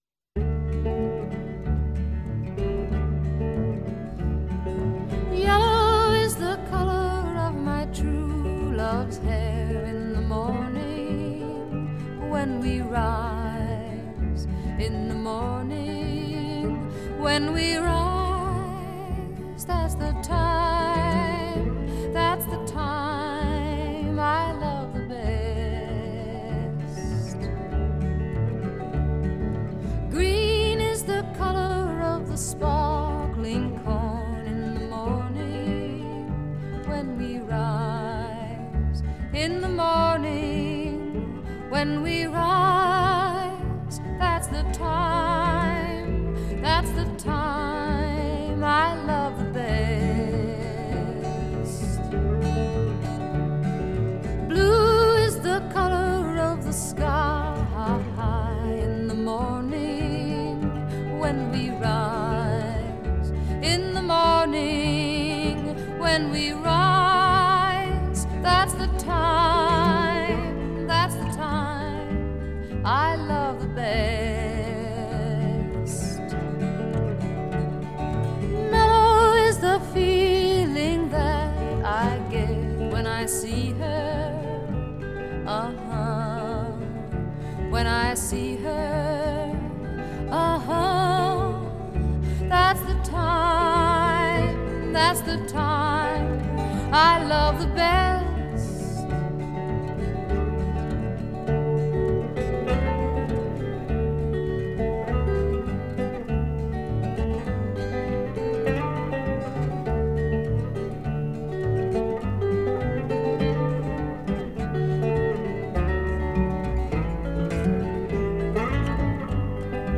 撼動心弦的顫音，悠揚柔軟而充滿強韌勁道的高音演唱